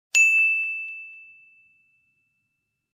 Tải âm thanh "Correct (ding)" - Hiệu ứng âm thanh chỉnh sửa video